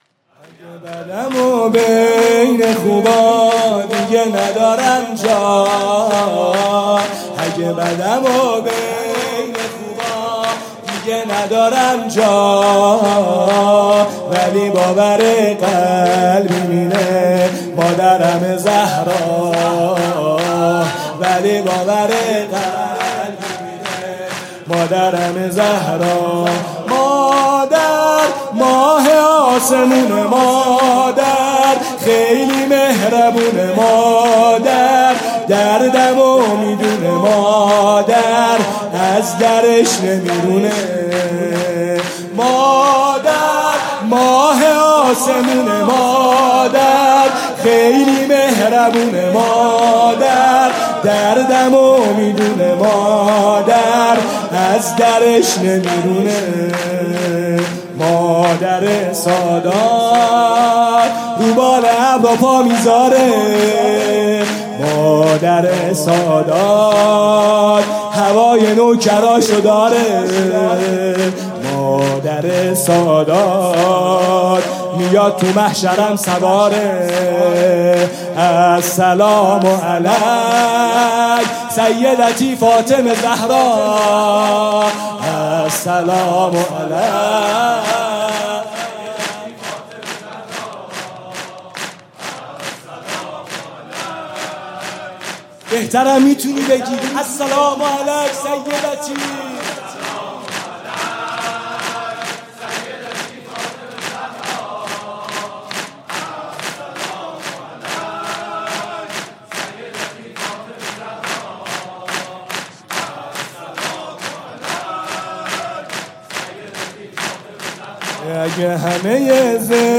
شب اول فاطمیه دوم ۱۴۰۴